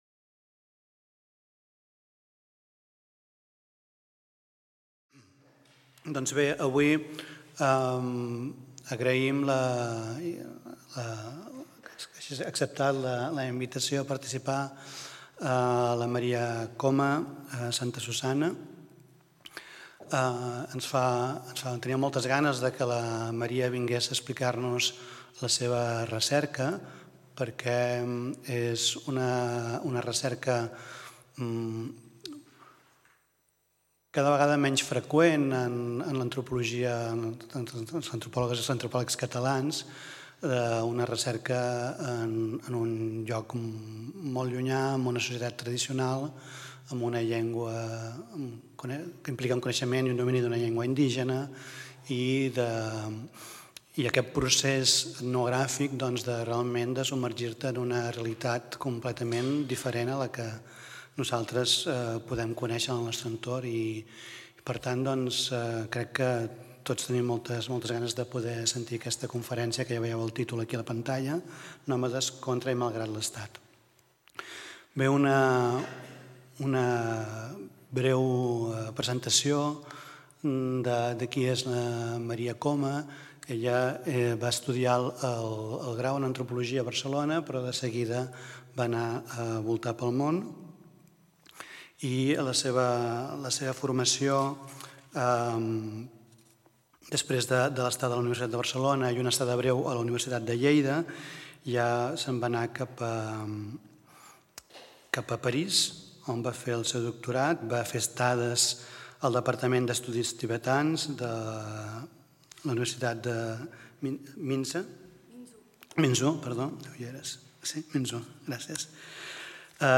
Conferència d'antropologia social